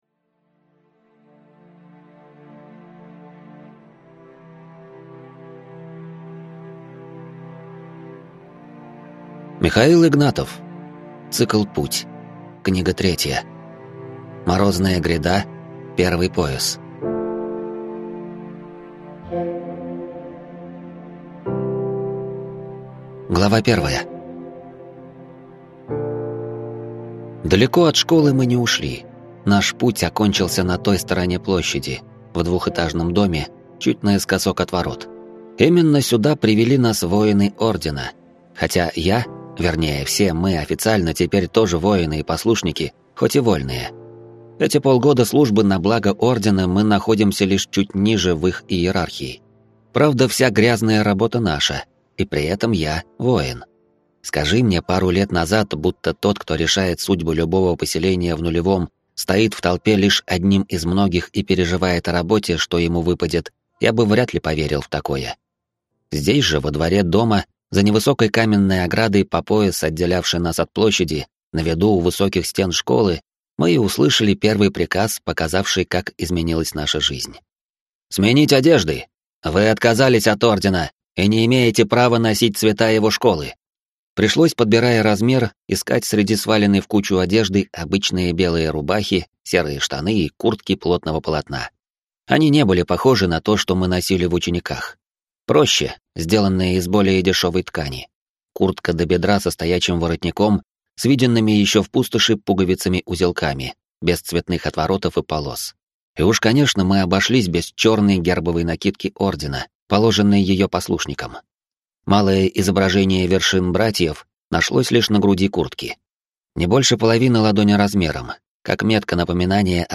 Аудиокнига Морозная Гряда. Первый пояс | Библиотека аудиокниг